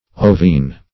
Ovine \O"vine\ ([=o]"v[imac]n), a. [L. ovinus, fr. ovis sheep: